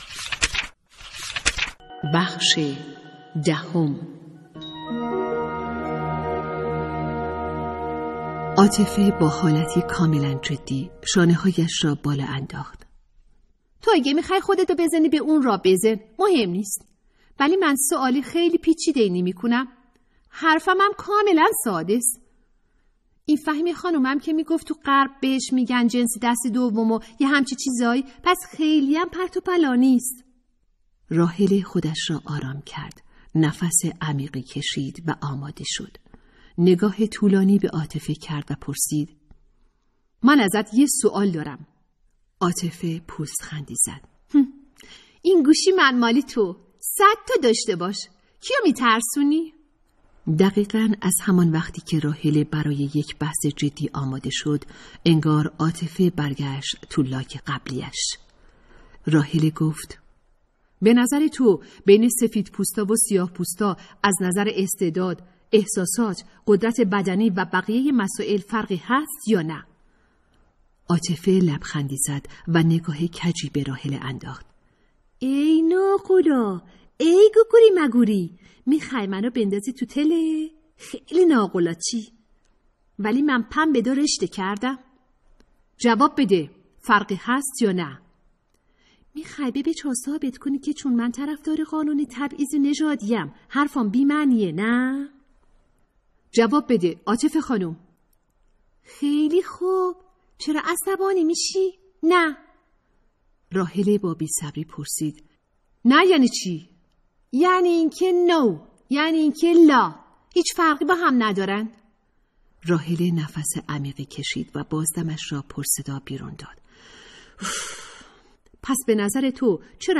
کتاب صوتی | دختران آفتاب (10)